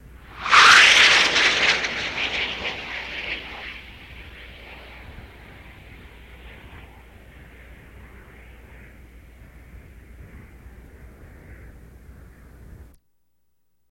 F-104 Pass By